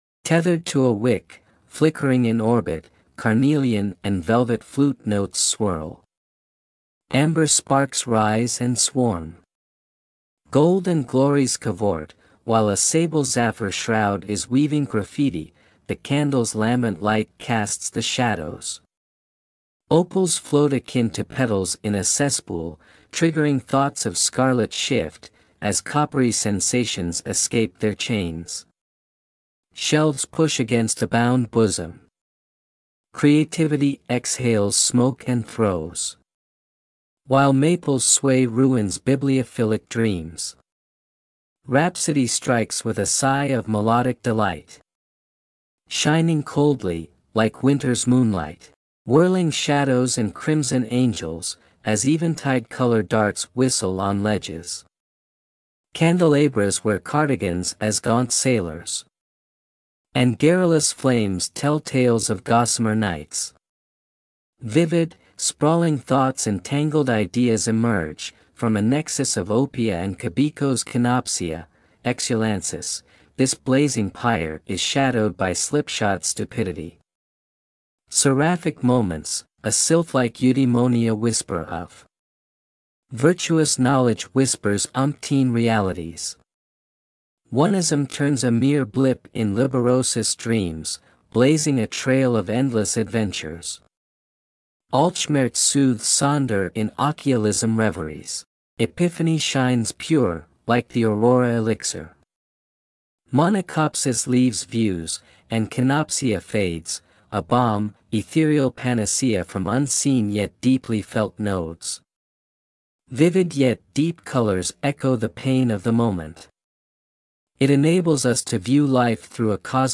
It almost strikes me a little trippy.
The choice of music you selected doesn't hurt either!!!